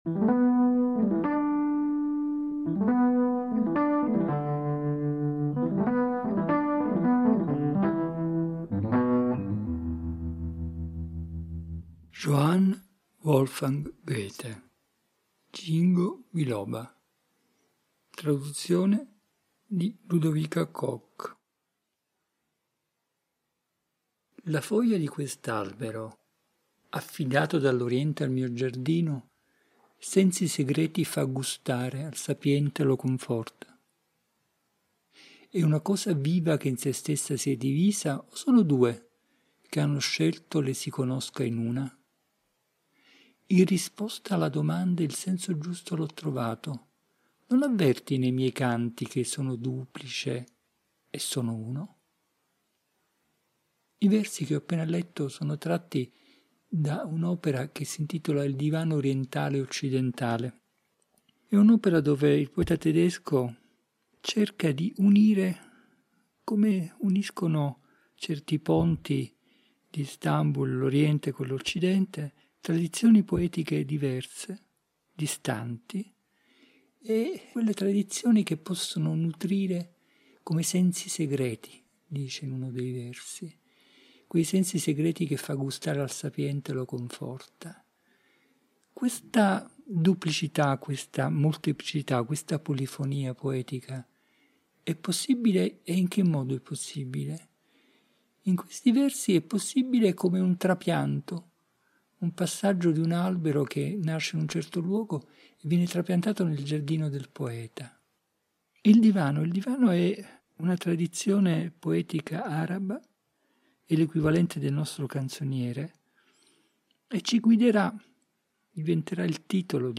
Ed è a partire da questo simbolo "delle equazioni casalinghe" che hanno caratterizzato il tempo del lockdown (ogni registrazione è stata pensata e realizzata fra le mura domestiche) che egli ci guida nella rigogliosa selva della parola poetica per "dare aria ai pensieri".